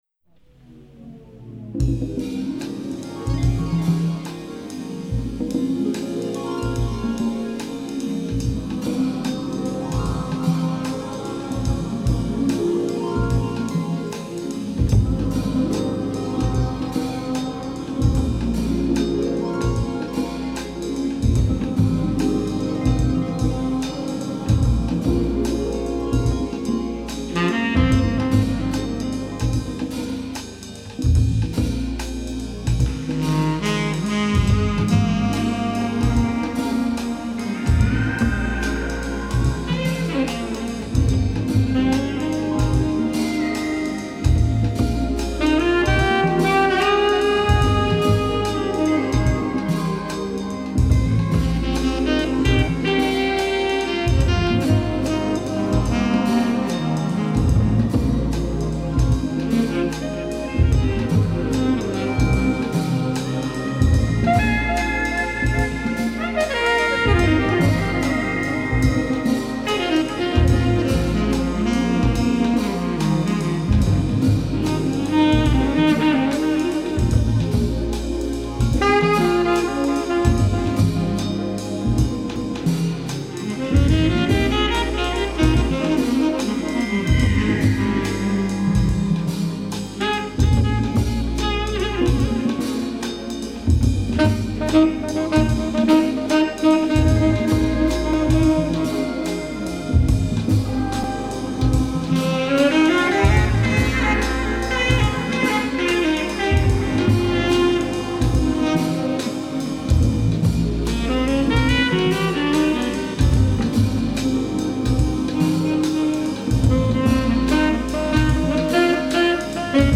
Un disque de jazz orchestral, luxuriant et atmosphérique…